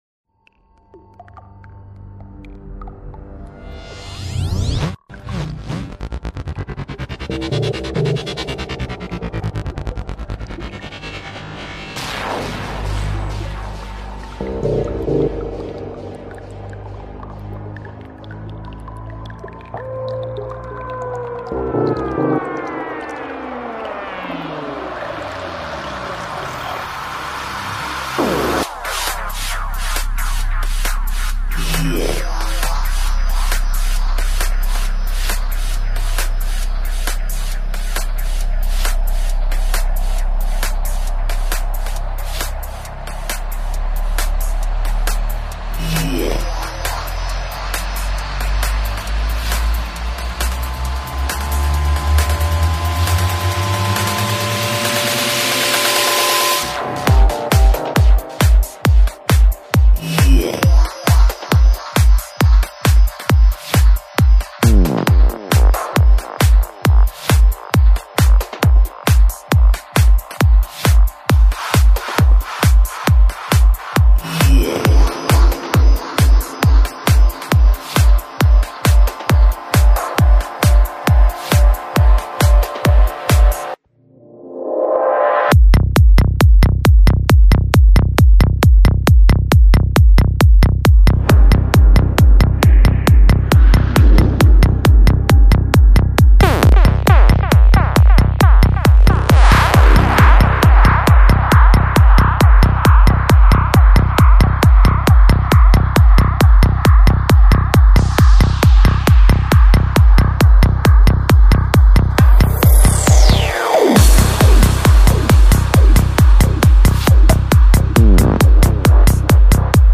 música electrónica